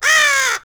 raven.wav